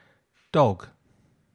Pronunciación
/k/  - dock          /g/ - dog